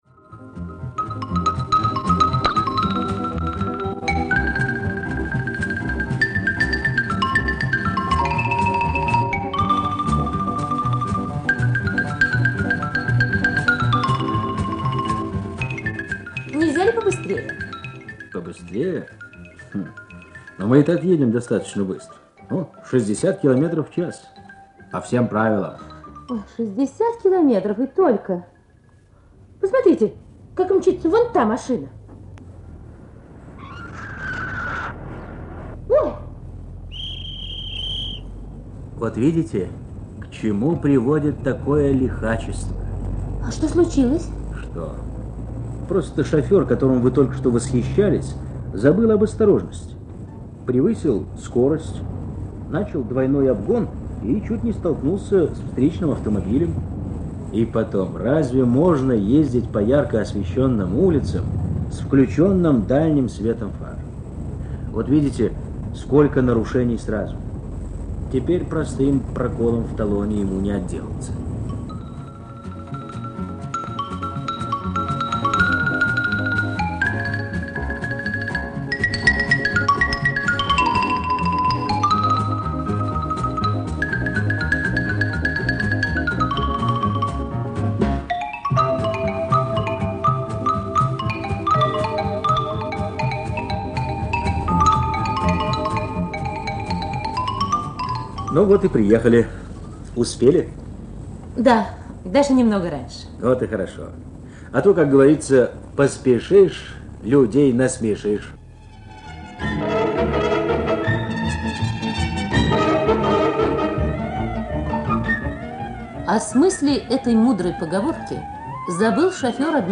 Тема - какие бывают таксисты: плохой таксист - хороший таксист; пьяный таксист - трезвый таксист. Оцифровка старой ленты.